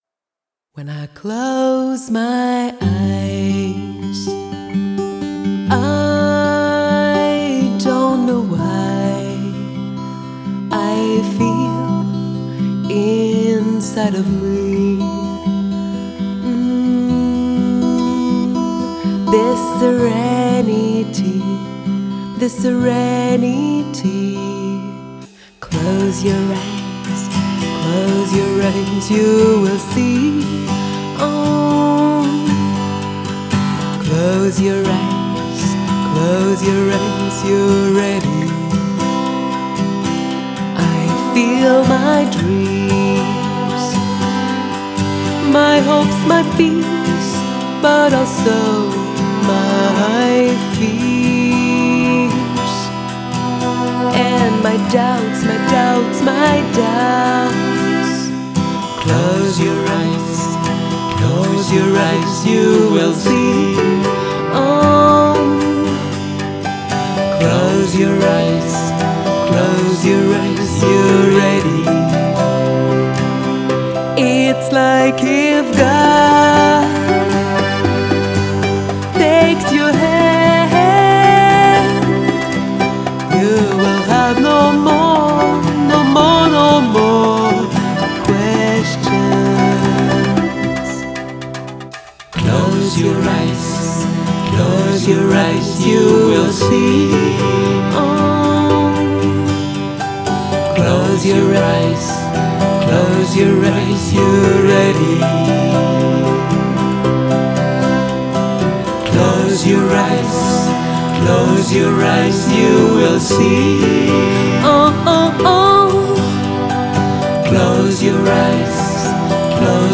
Genre: Folk, Pop